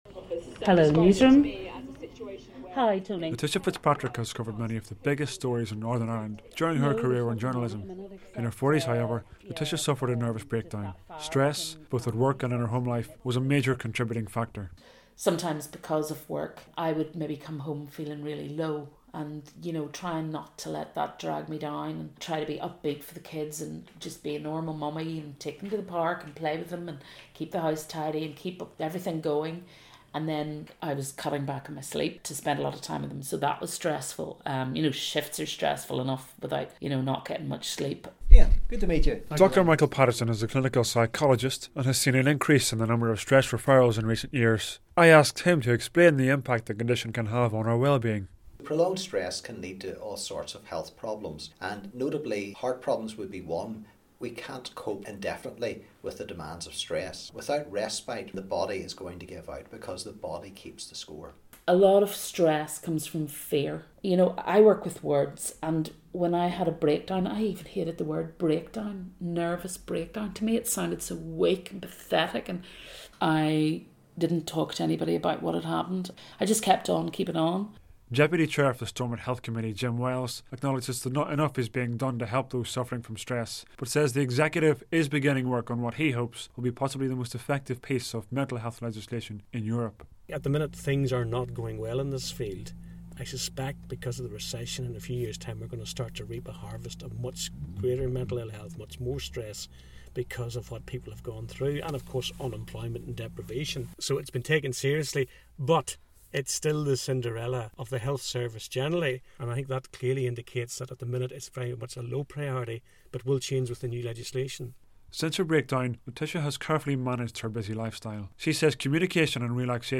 talks about her struggle with stress in this report into one of the biggest mental health problems in the UK.